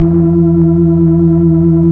Index of /90_sSampleCDs/Keyboards of The 60's and 70's - CD1/KEY_Optigan/KEY_Optigan Keys